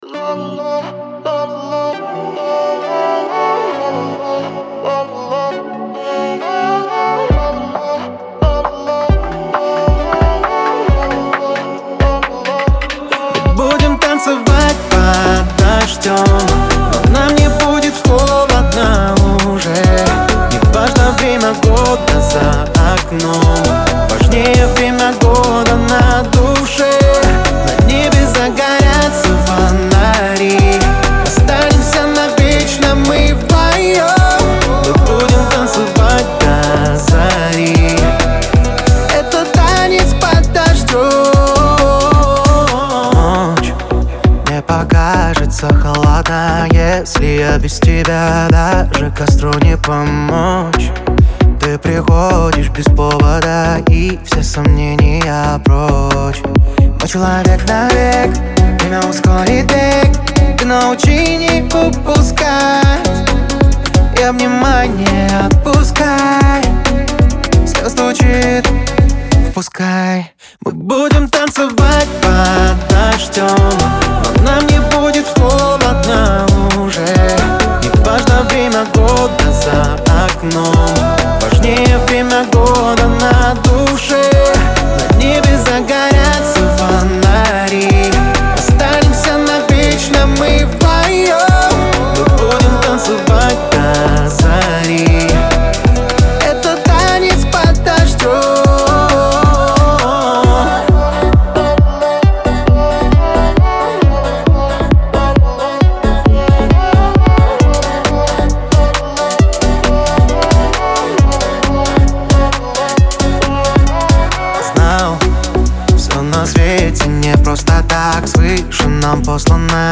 эстрада
pop